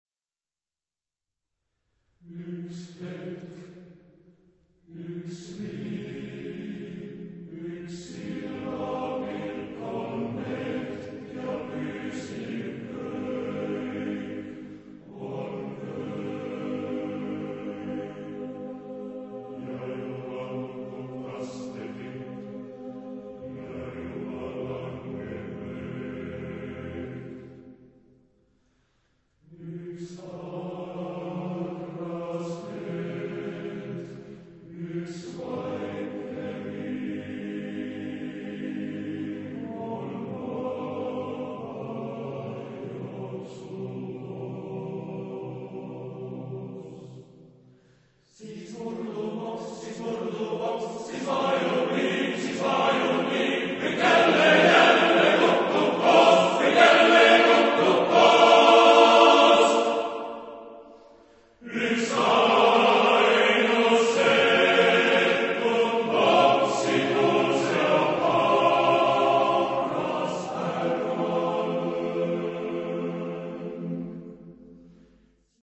Genre-Style-Forme : Profane ; Pièce vocale
Type de choeur : TTBB  (4 voix égales d'hommes )